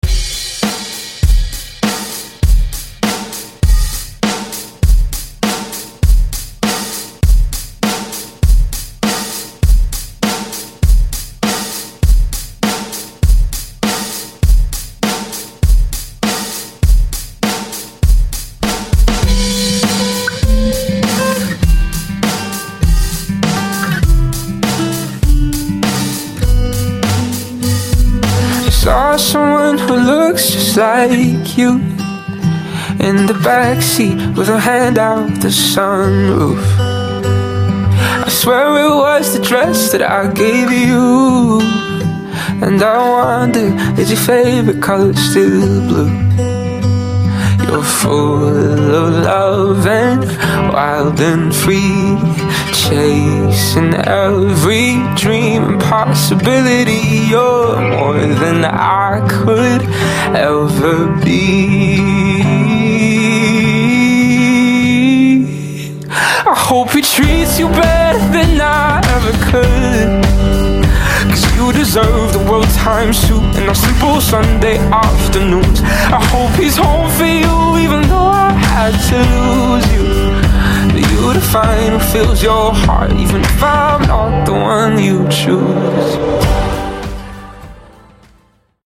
BPM: 100 Time